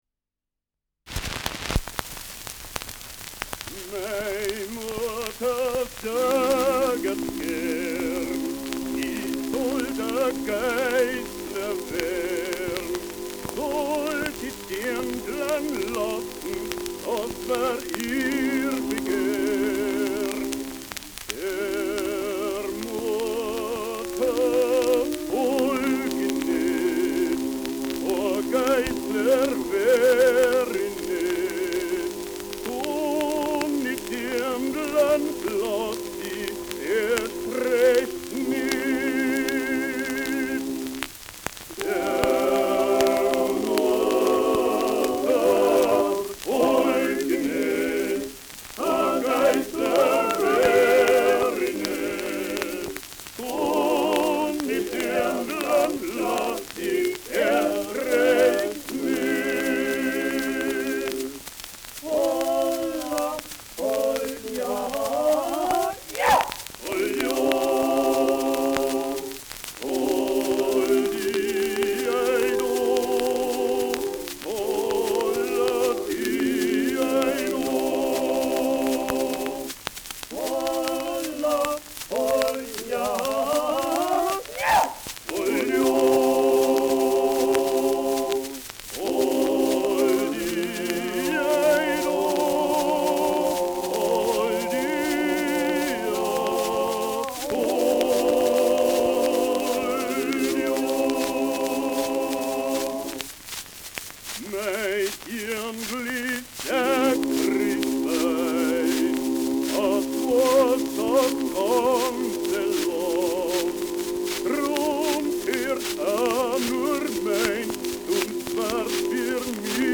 Schellackplatte
Stärkeres Grundrauschen : Durchgehend leichtes bis stärkeres Knacken : Nadelgeräusche ab zweiten Drittel
Doppel-Quartett D' Brünstoana (Interpretation)
[München] (Aufnahmeort)